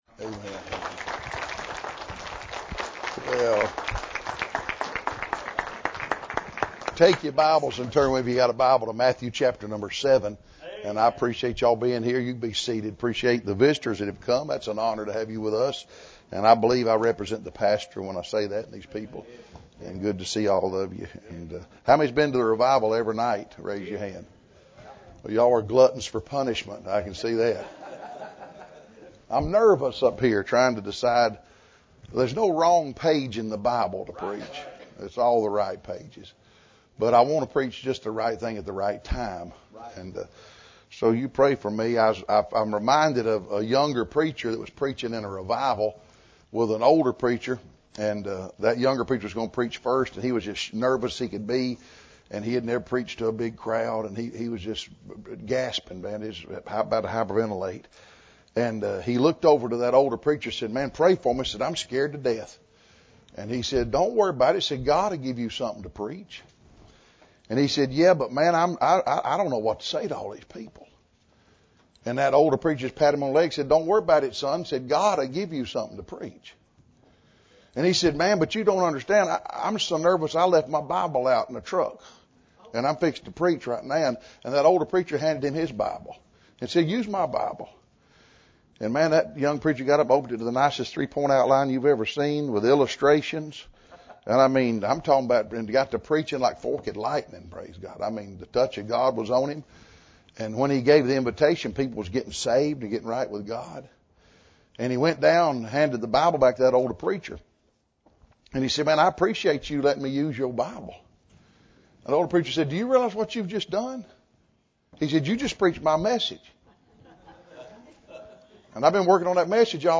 This sermon is a sober reminder from the words of Christ that life is short, eternity is long, and Jesus sets the terms for entrance into heaven. The Lord Jesus warns of a real hell, of false teachers who mislead, and of many who assume they will be saved when, in fact, they are not.